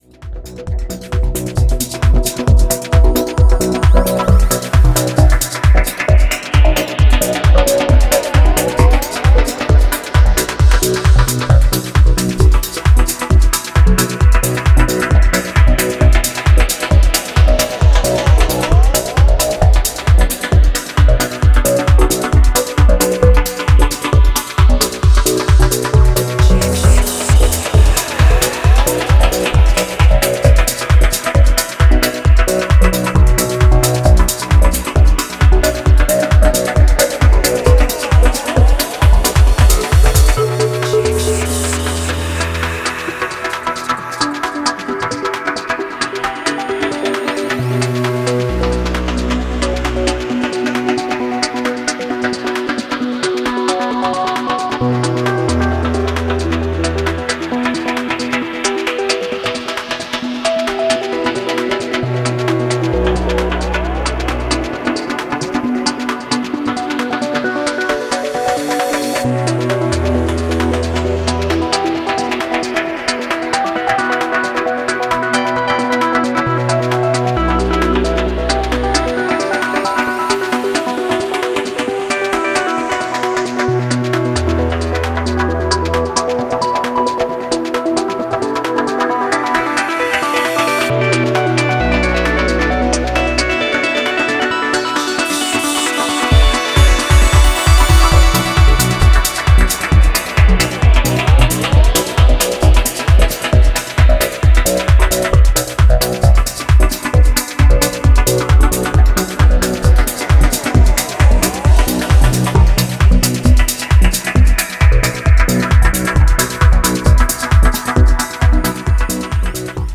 スタッカートしたリフとバウンシーなキックが軽快にダンシングハイへと誘う